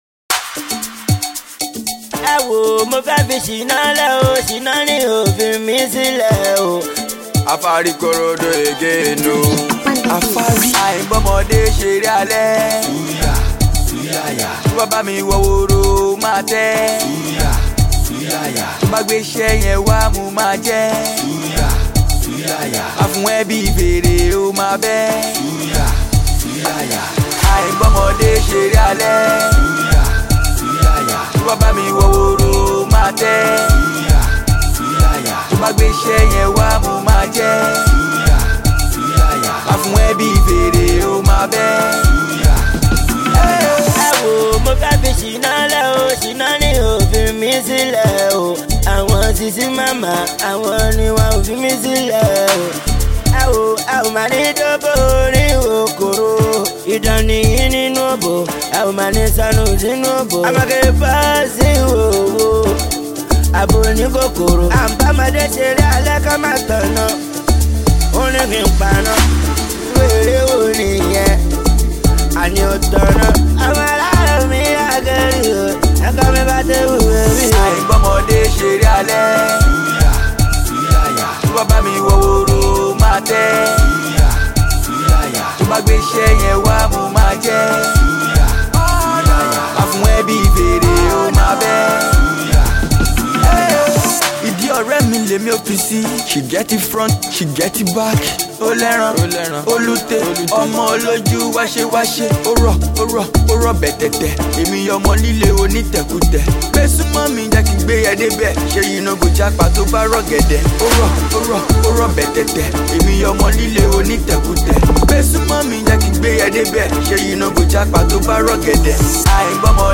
street-hop
street anthem